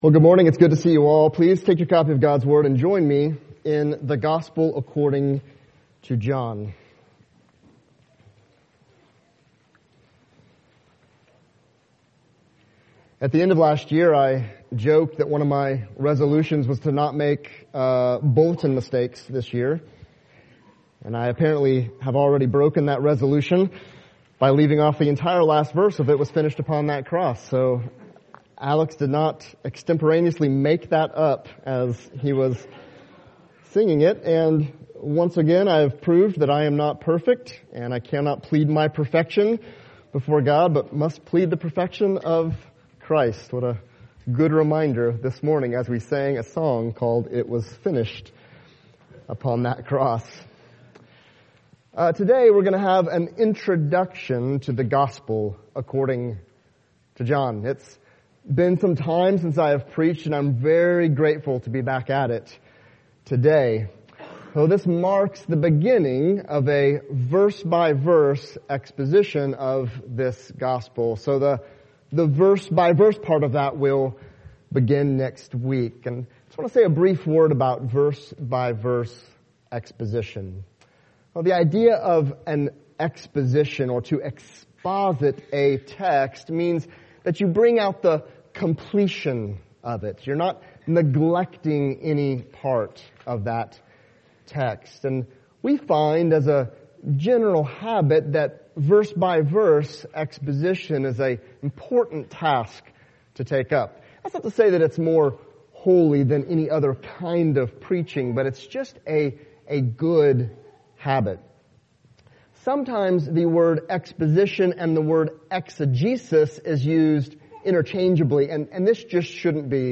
John Service Type: Sunday Topics